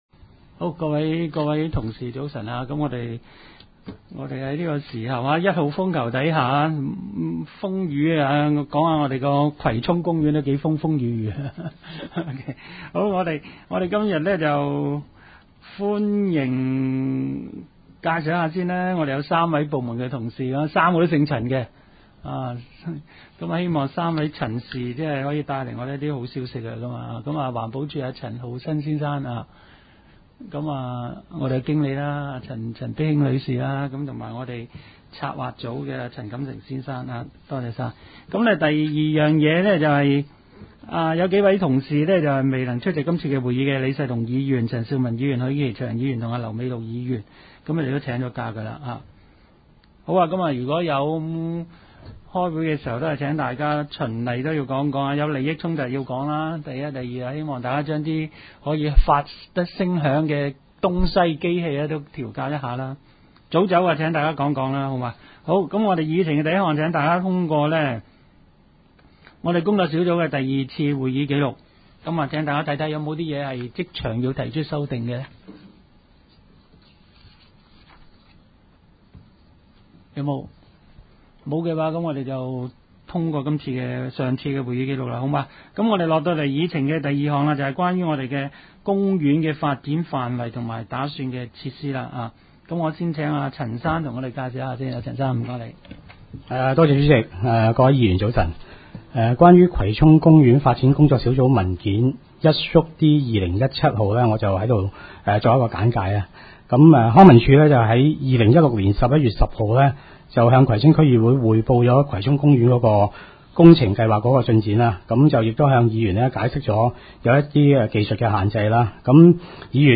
工作小组会议的录音记录